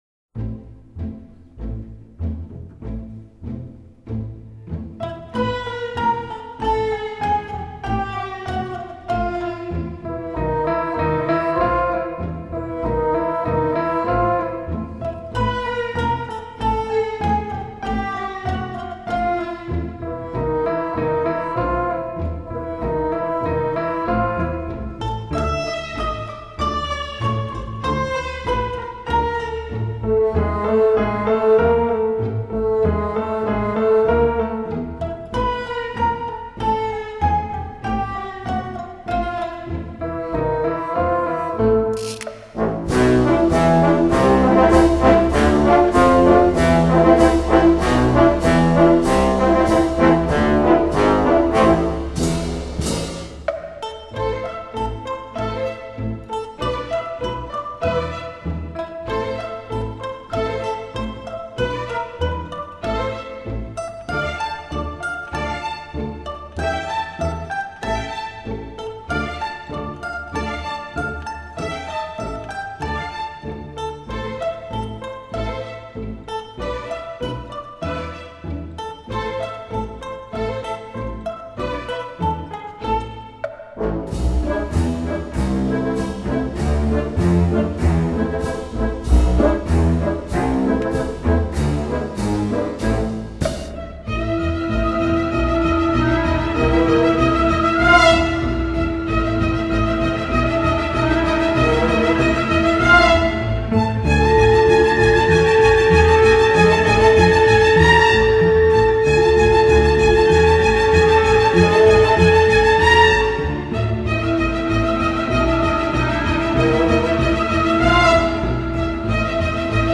Genre:Score